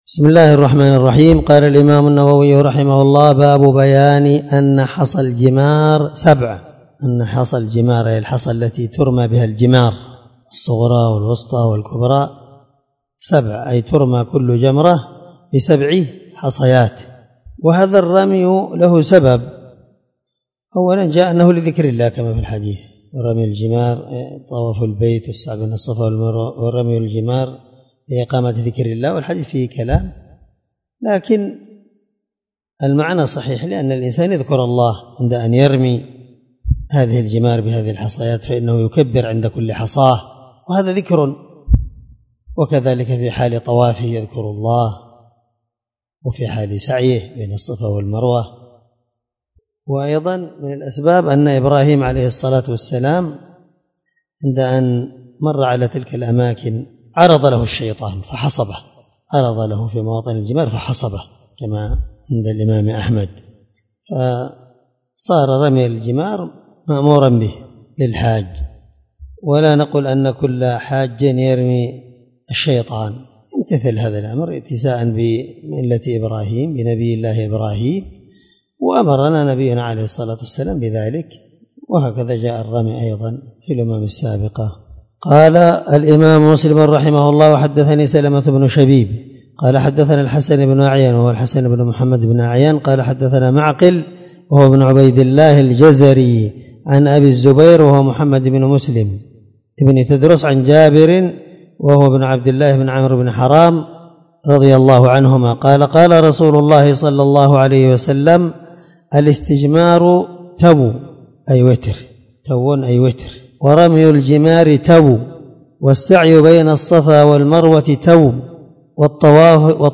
الدرس50من شرح كتاب الحج حديث رقم(1300) من صحيح مسلم